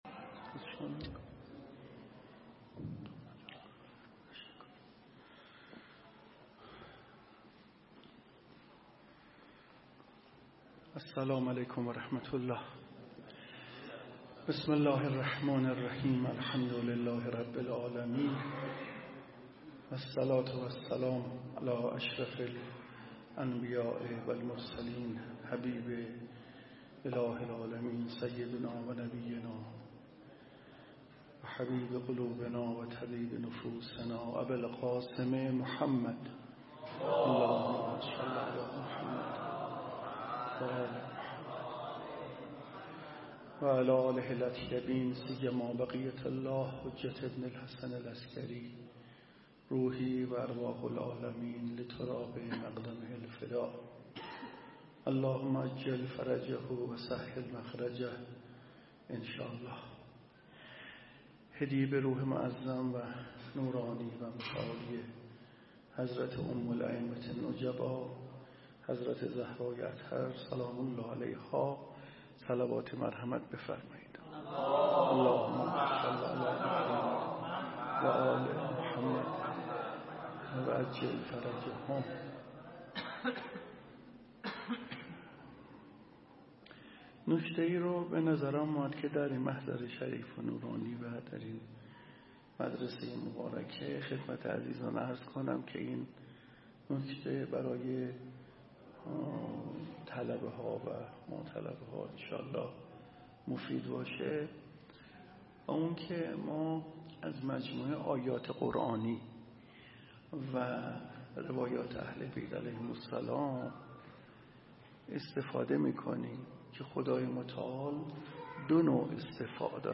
هیئت مدرسه